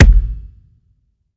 drum-hitnormal.wav